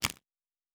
Cards Place 08.wav